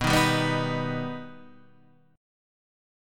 B Augmented